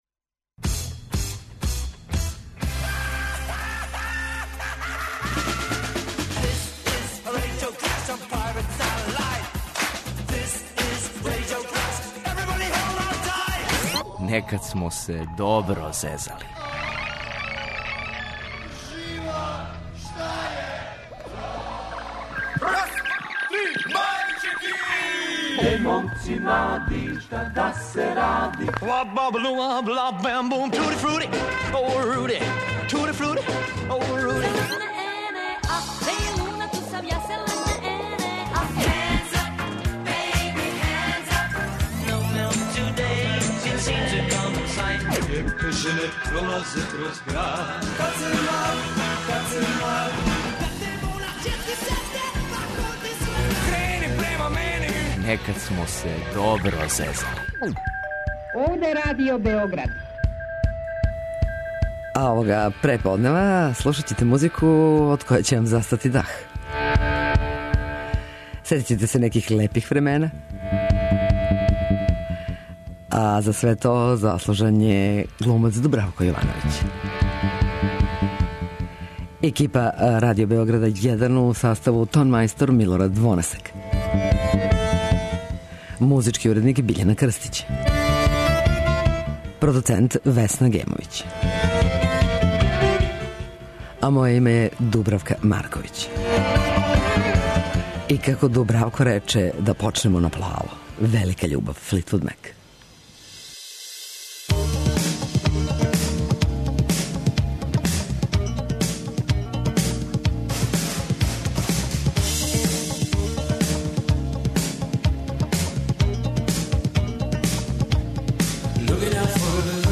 Слушаћемо музику која ће нама измамити осмех.